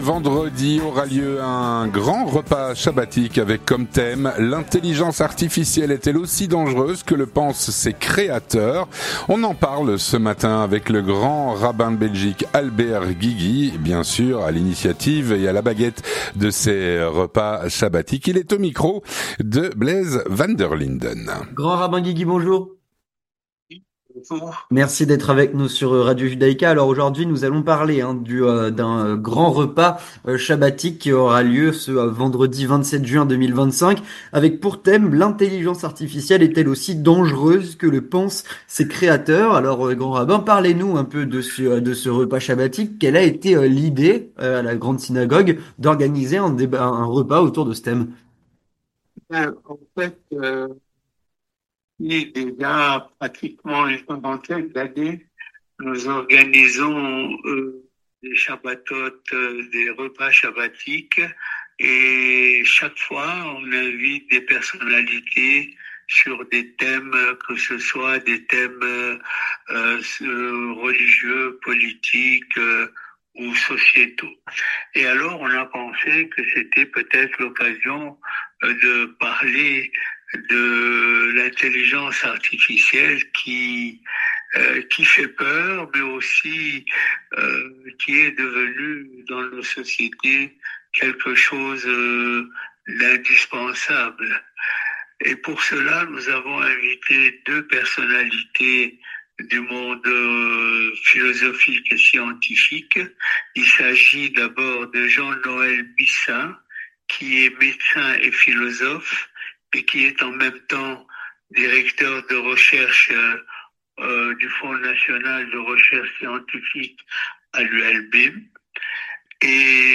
On en parle avec le Grand Rabbin Guigui, Grand Rabbin de Bruxelles.